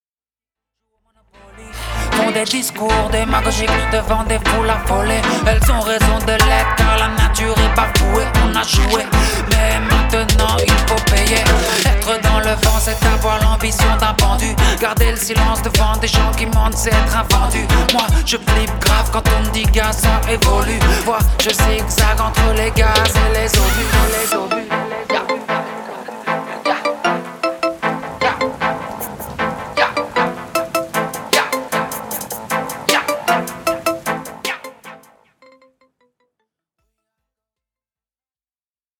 Le reggae reste néanmoins le fil conducteur de cet EP.